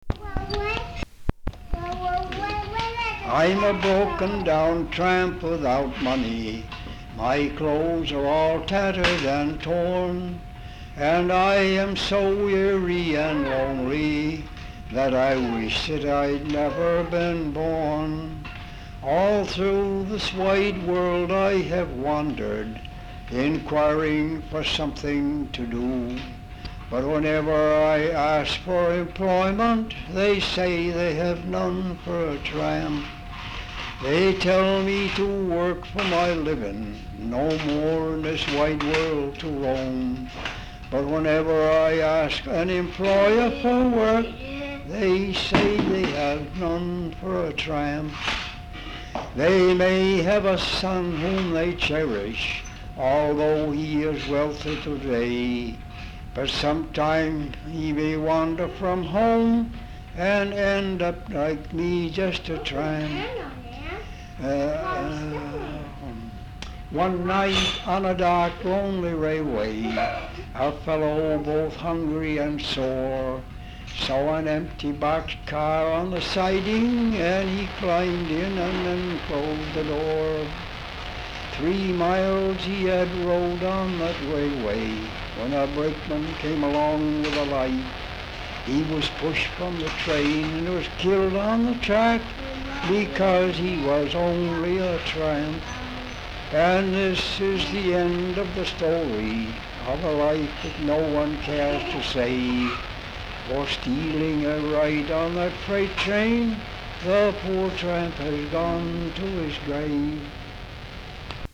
Folk songs, English--Vermont
sound tape reel (analog)
Location Dover, Vermont